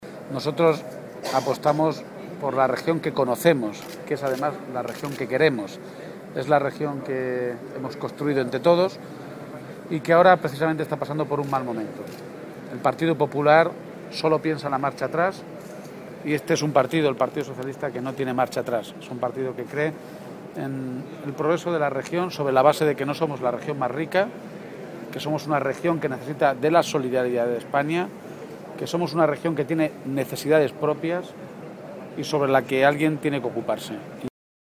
García-Page atiende a los medios de comunicación.
El secretario general del PSOE de Castilla-La Mancha, Emiliano García-Page, clausuró el Congreso provincial de los socialistas albaceteños
Garcia_Page-congreso_PSOE_AB-3.mp3